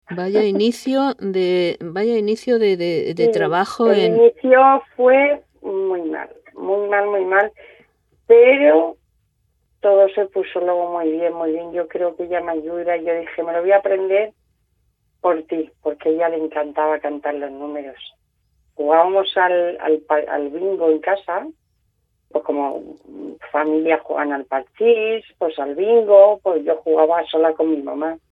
con naturalidad formato MP3 audio(0,54 MB).